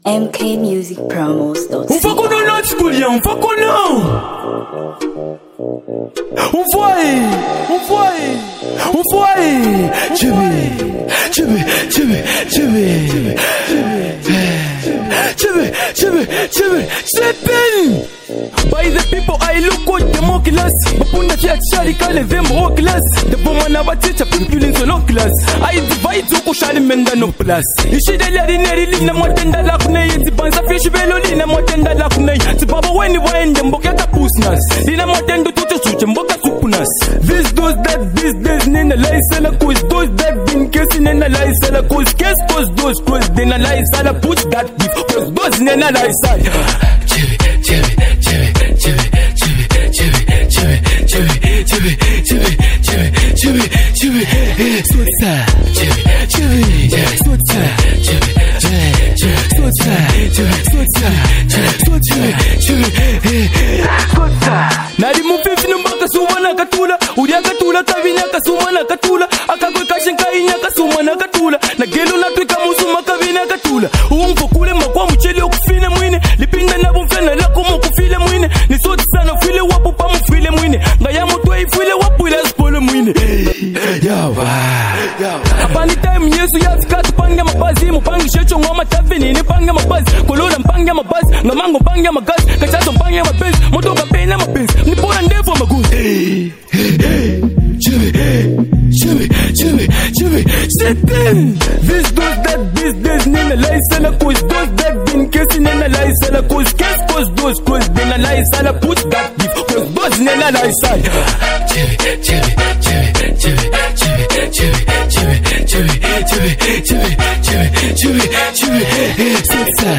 Zambian Hip-Hop 2026